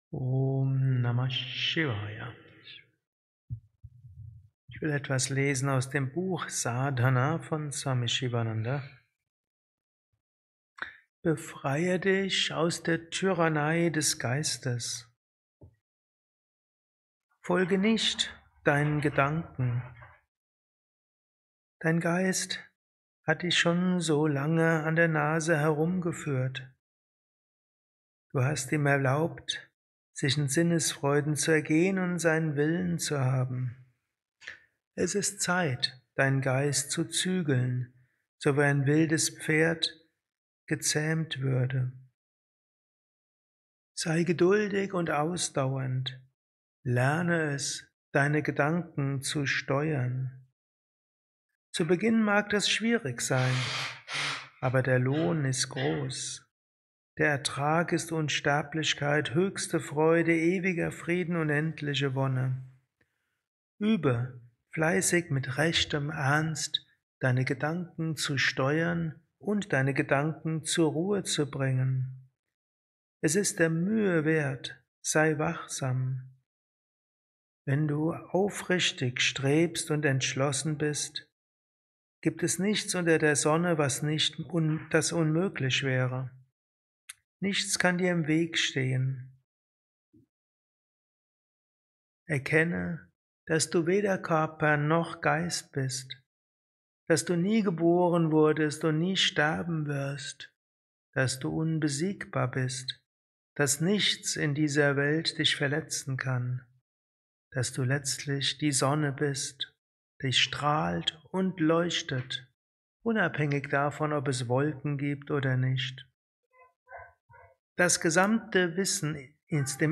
kurzer Vortrag als Inspiration für den heutigen Tag von und mit
Satsangs gehalten nach einer Meditation im Yoga Vidya Ashram Bad